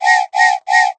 factory_alarm_7.ogg